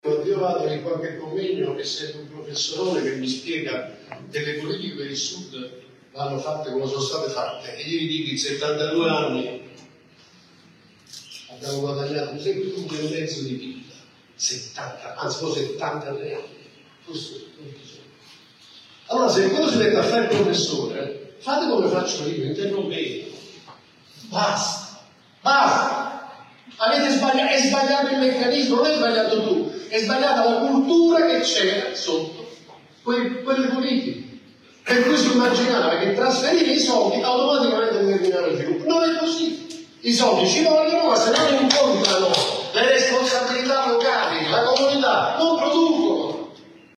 Partendo da questa premessa, Fondazione con il Sud ha organizzato al Rione Parco Verde di Caivano, a Napoli, l’incontro “Un futuro già visto”.